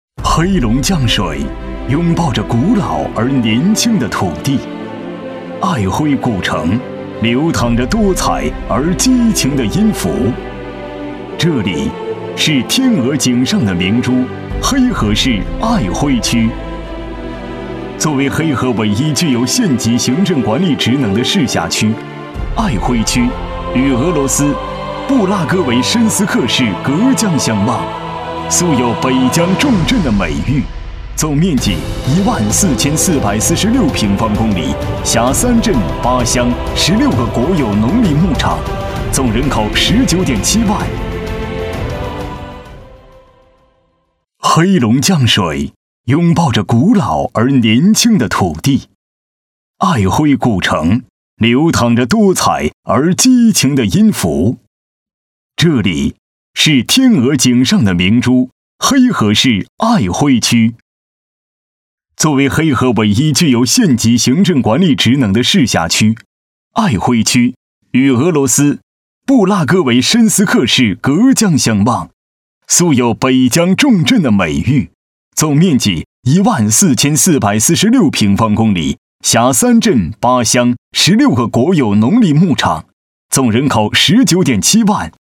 政府专题配音